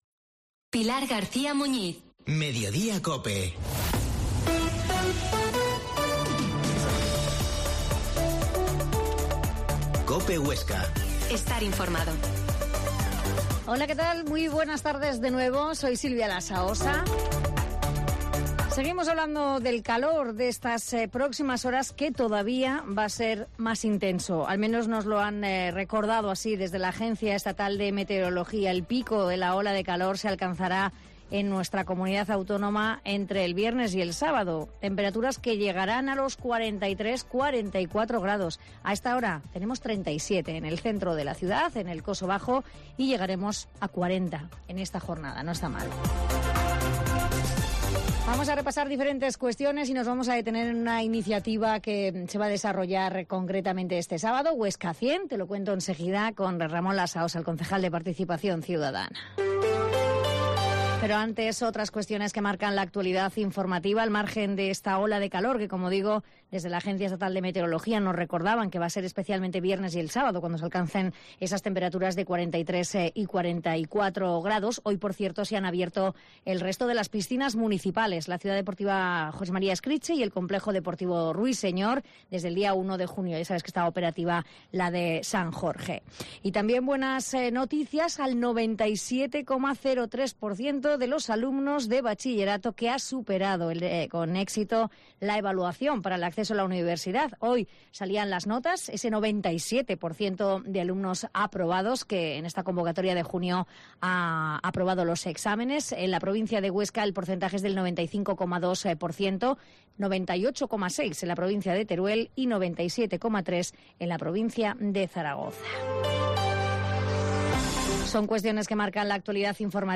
Mediodia en COPE Huesca 13.50h Entrevista al concejal de participación ciudadana, Ramón Lasaosa